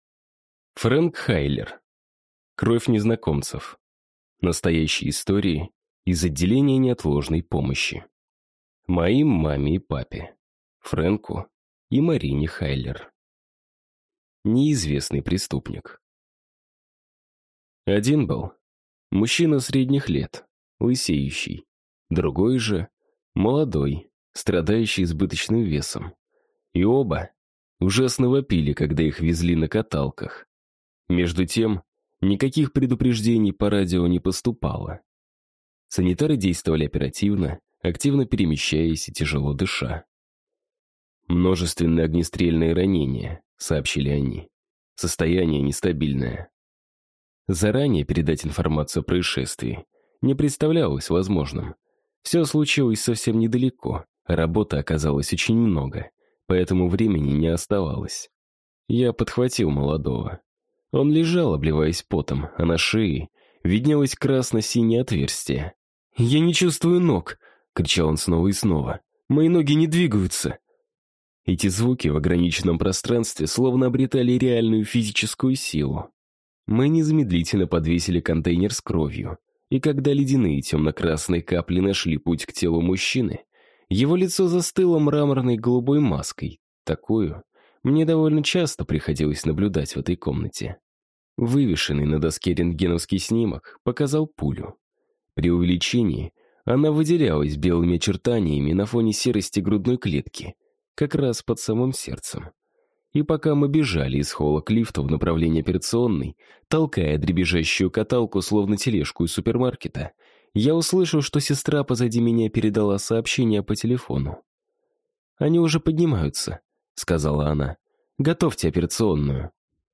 Аудиокнига Кровь незнакомцев. Настоящие истории из отделения неотложной помощи | Библиотека аудиокниг